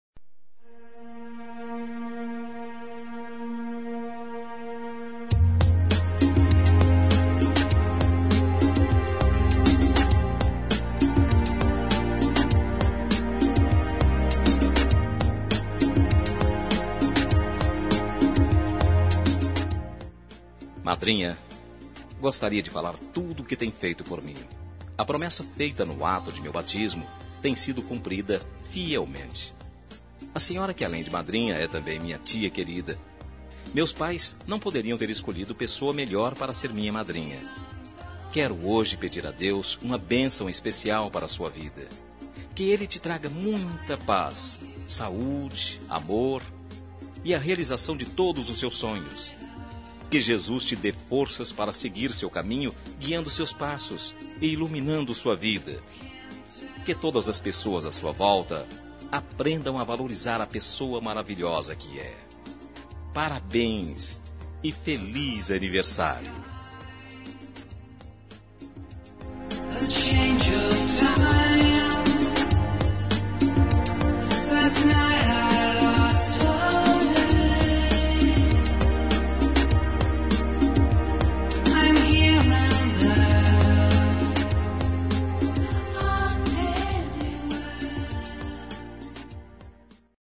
Telemensagem Aniversário de Tia – Voz Masculina – Cód: 2011 – Tia / Madrinha